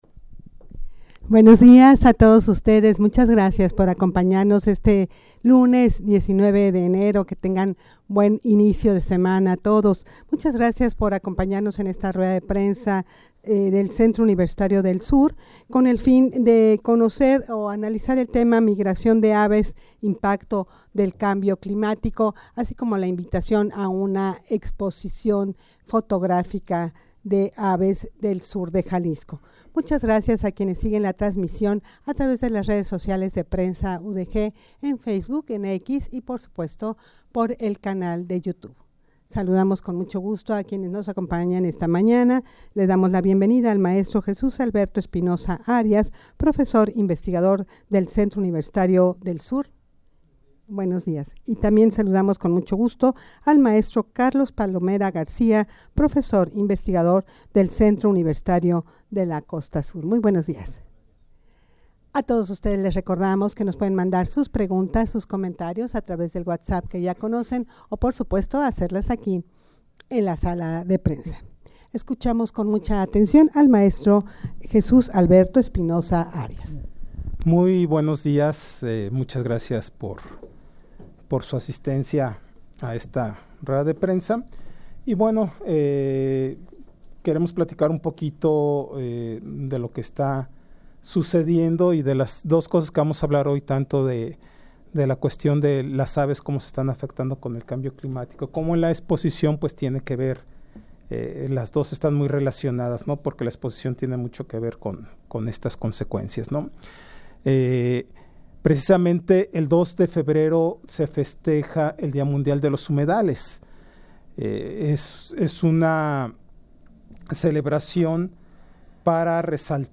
Audio de la Rueda de Prensa
rueda-de-prensa-migracion-de-aves-impacto-del-cambio-climatico.mp3